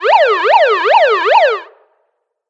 alarmPoliceSiren.ogg